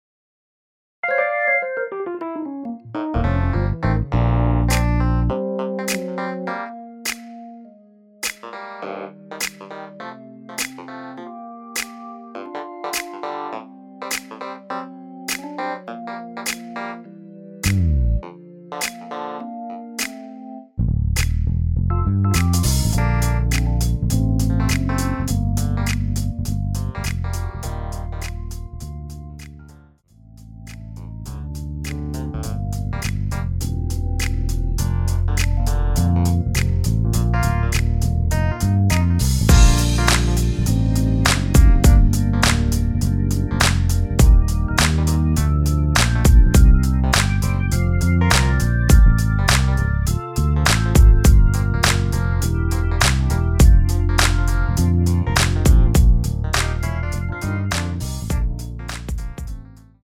MR은 2번만 하고 노래 하기 편하게 엔딩을 만들었습니다.(본문의 가사와 코러스 MR 미리듣기 확인)
원키에서(-2)내린 MR입니다.
Bb
앞부분30초, 뒷부분30초씩 편집해서 올려 드리고 있습니다.
중간에 음이 끈어지고 다시 나오는 이유는